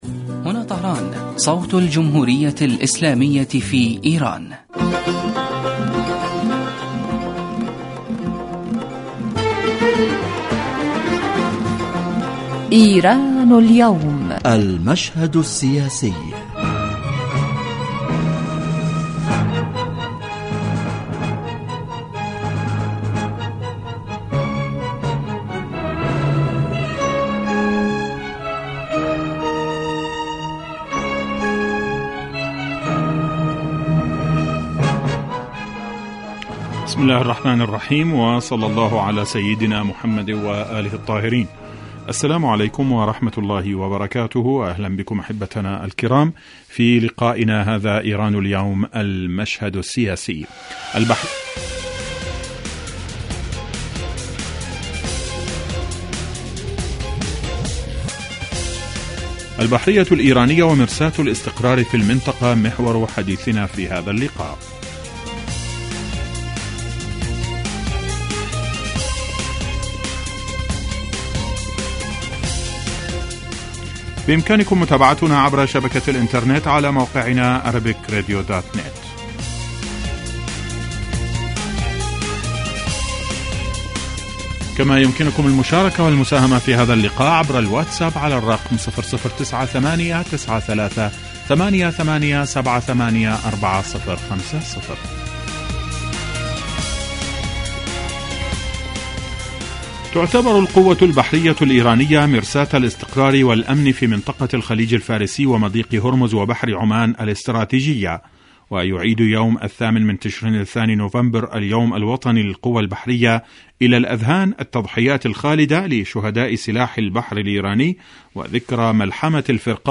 يتناول هذا البرنامج كما هو واضح من تسميته آخر القضايا والأحداث الإيرانية ويختص كل أسبوع بموضوع من أهم موضوعات الساعة في ايران وتأثيره على الساحة الإقليمية ويتطرق إليه ضيف البرنامج في الاستوديو كما يطرح نفس الموضوع للمناقشة وتبادل النظر على خبير آخر يتم استقباله على الهاتف